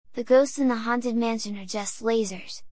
Play, download and share haunted lasers original sound button!!!!
haunted-lasers.mp3